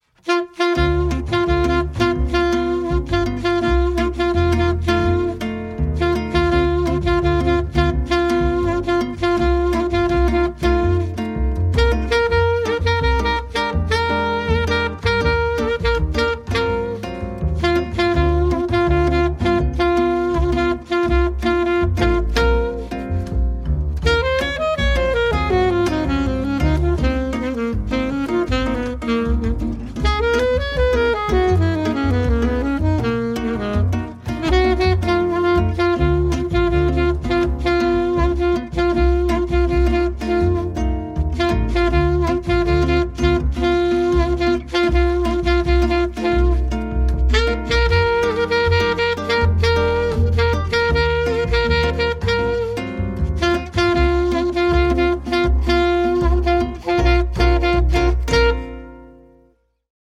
Instrumental Rat-Pack Jazz Band